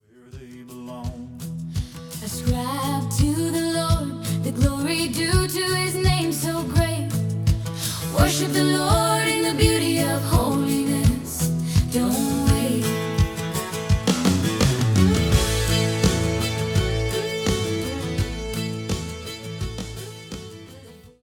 authentic Country worship
the authentic sound of modern Country worship
From intimate acoustic moments to full-band celebrations